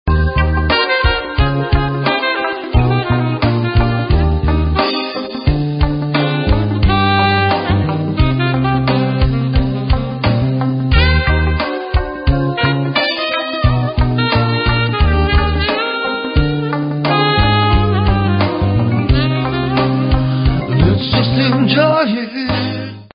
Recorded at: Barrie, Ontario 2002.